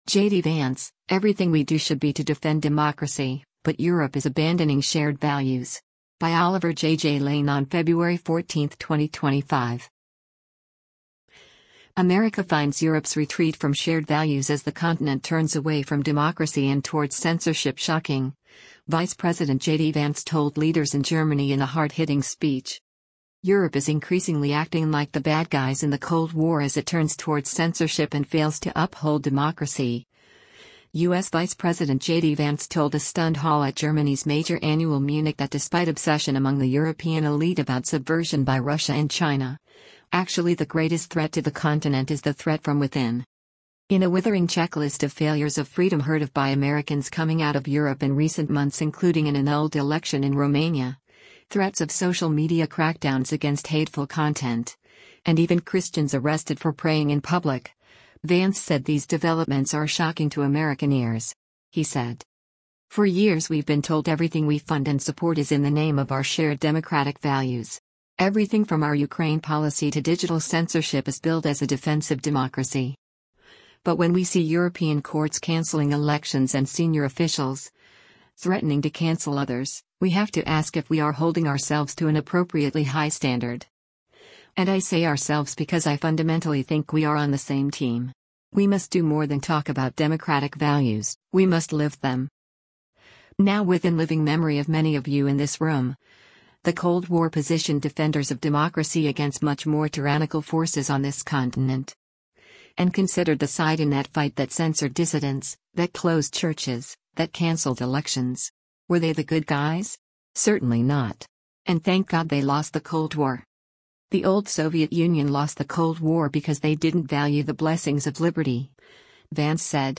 US Vice President JD Vance delivers his speech during the 61st Munich Security Conference